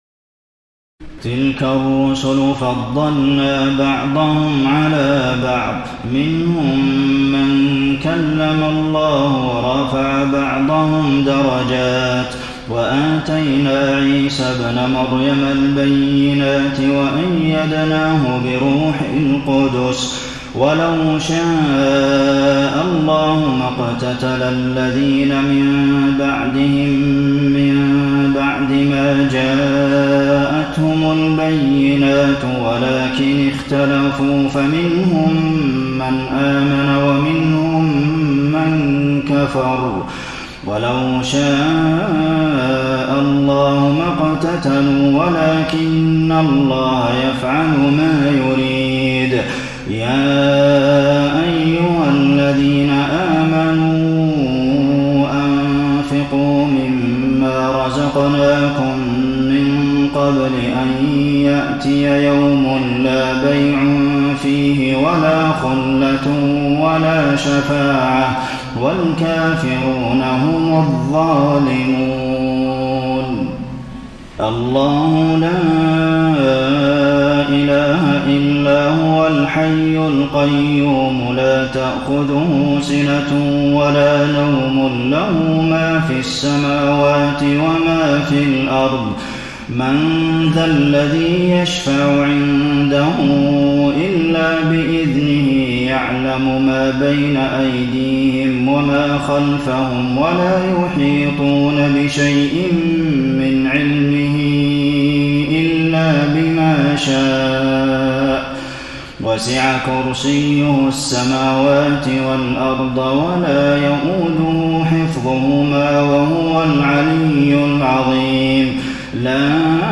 تراويح الليلة الثالثة رمضان 1433هـ من سورتي البقرة (253-286) و آل عمران (1-13) Taraweeh 3st night Ramadan 1433H from Surah Al-Baqara and Surah Aal-i-Imraan > تراويح الحرم النبوي عام 1433 🕌 > التراويح - تلاوات الحرمين